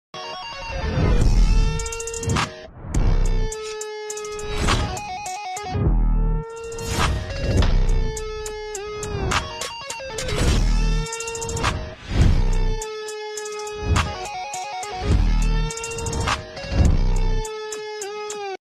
Láng đĩa phanh cho Mitsubishi sound effects free download